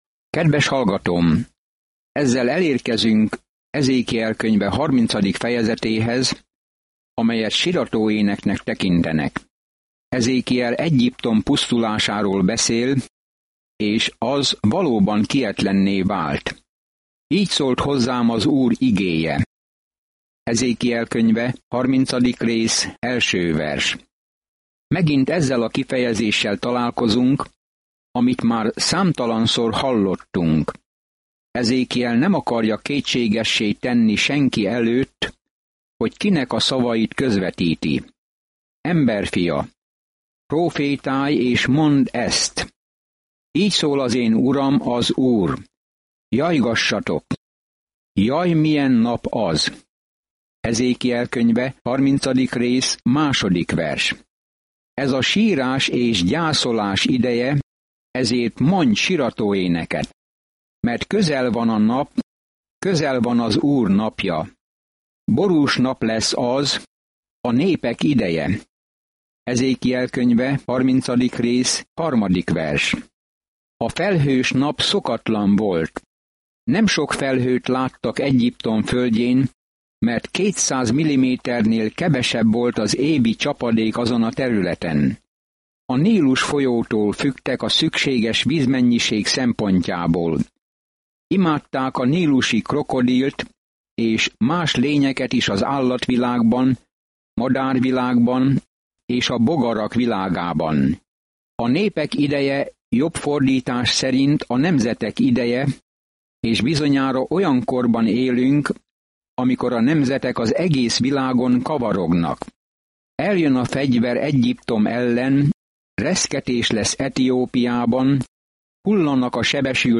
Szentírás Ezékiel 30 Ezékiel 31:1-15 Nap 17 Olvasóterv elkezdése Nap 19 A tervről Az emberek nem hallgattak Ezékiel figyelmeztető szavaira, hogy térjenek vissza Istenhez, ezért ehelyett az apokaliptikus példázatokat adta elő, és ez meghasította az emberek szívét. Napi utazás Ezékielben, miközben hallgatja a hangos tanulmányt, és olvassa kiválasztott verseket Isten szavából.